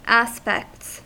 Ääntäminen
Ääntäminen US Haettu sana löytyi näillä lähdekielillä: englanti Käännöksiä ei löytynyt valitulle kohdekielelle. Aspects on sanan aspect monikko.